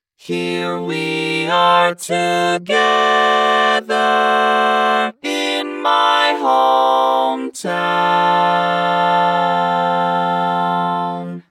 Key written in: F Major
How many parts: 4
Type: Female Barbershop (incl. SAI, HI, etc)
All Parts mix: